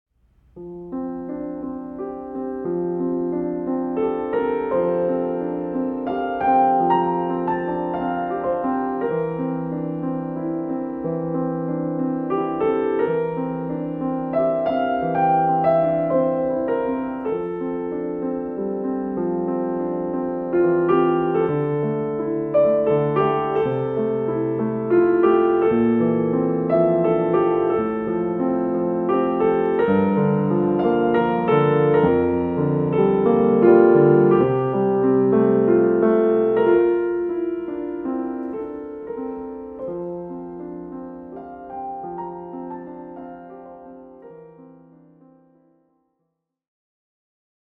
complete works for solo piano